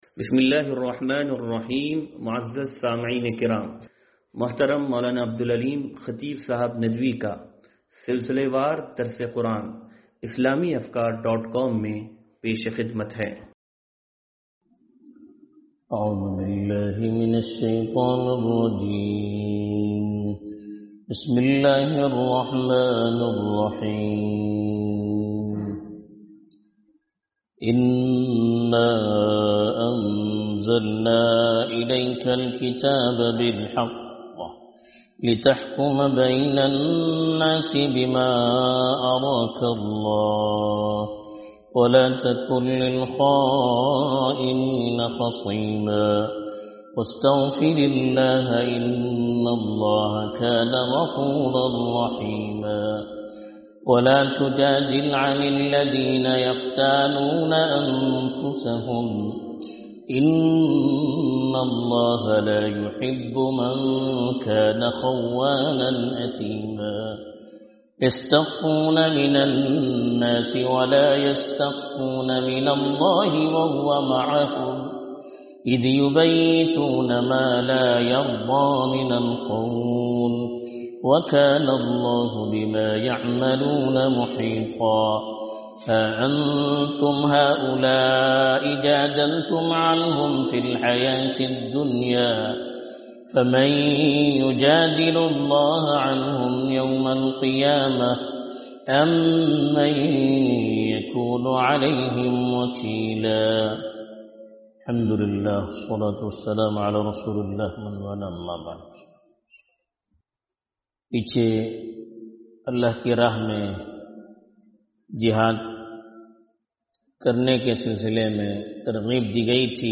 درس قرآن نمبر 0389